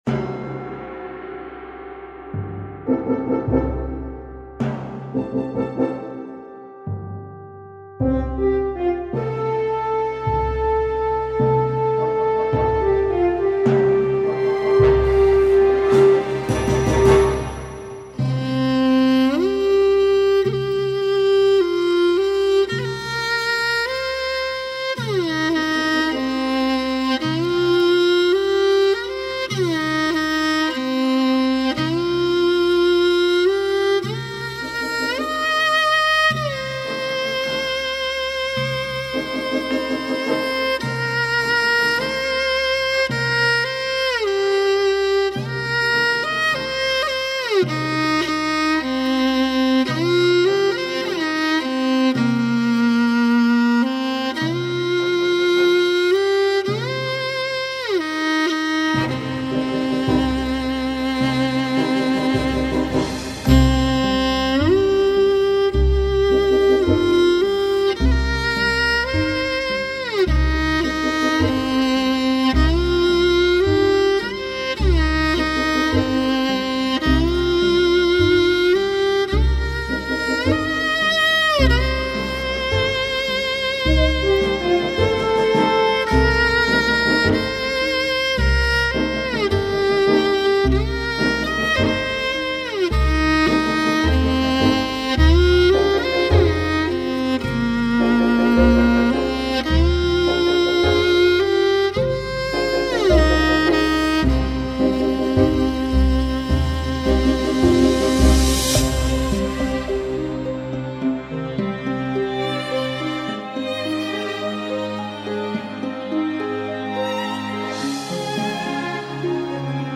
马头琴专辑